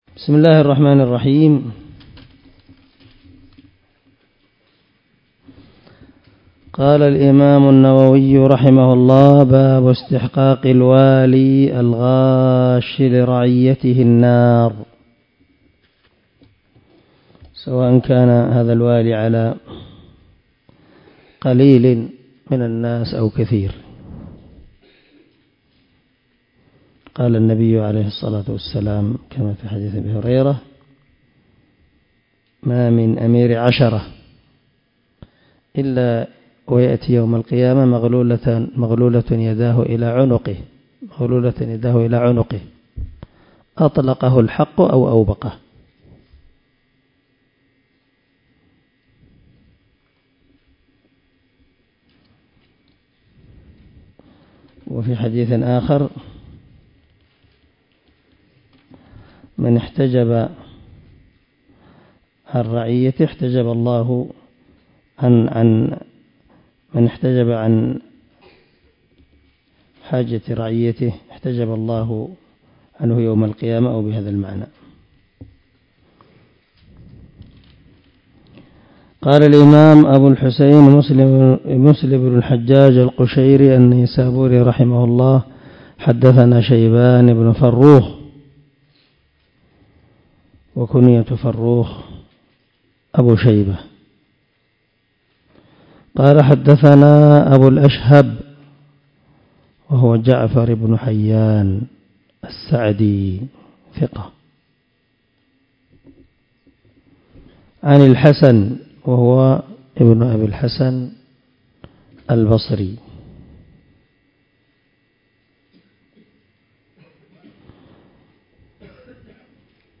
102الدرس 101 من شرح كتاب الإيمان حديث رقم ( 142 ) من صحيح مسلم